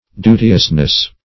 duteousness - definition of duteousness - synonyms, pronunciation, spelling from Free Dictionary
-- Du"te*ous*ness, n.
duteousness.mp3